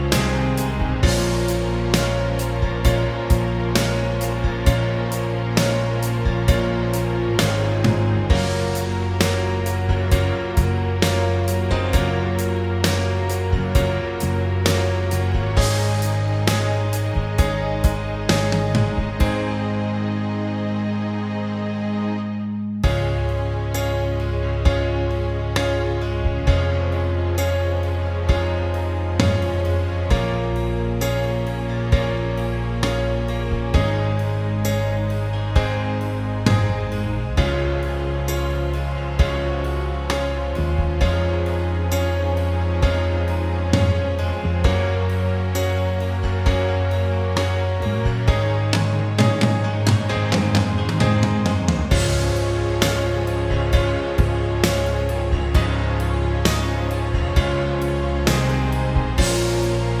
Minus Main Guitars For Guitarists 4:06 Buy £1.50